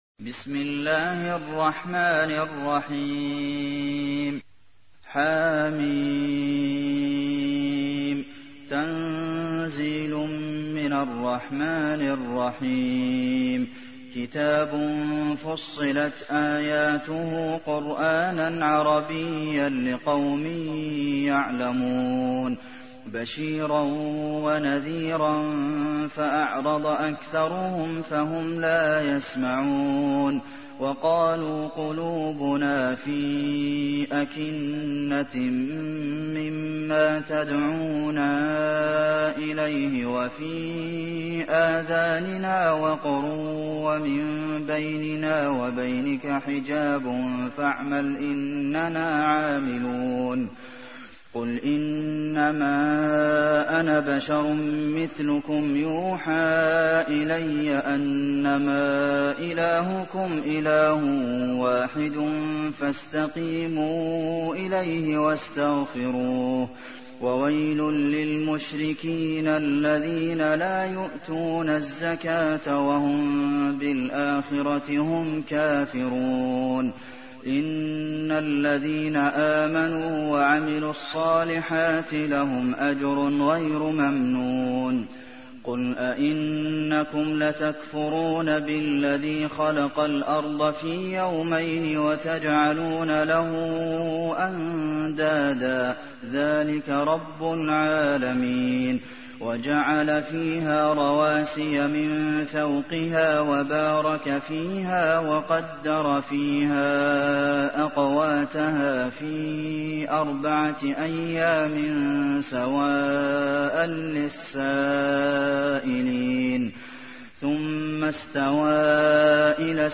تحميل : 41. سورة فصلت / القارئ عبد المحسن قاسم / القرآن الكريم / موقع يا حسين